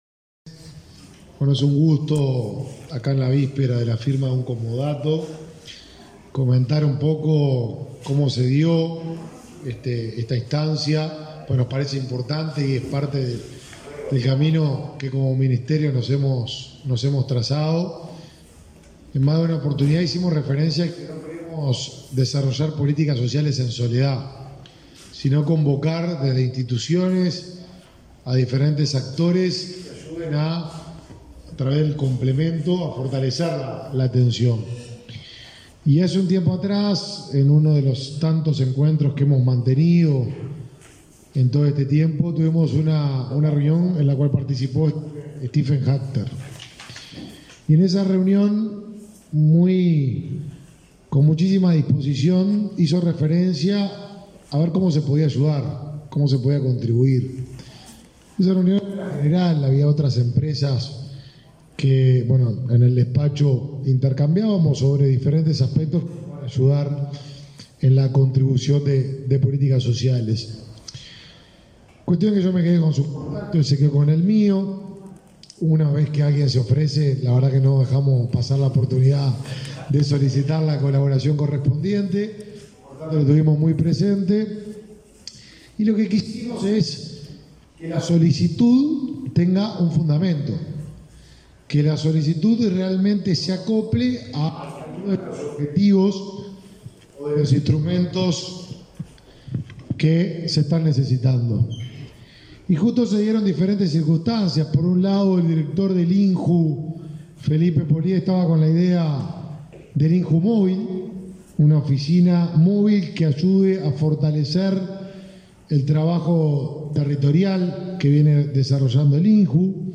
Palabras del ministro de Desarrollo Social, Martín Lema